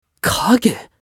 青年ボイス～ホラー系ボイス～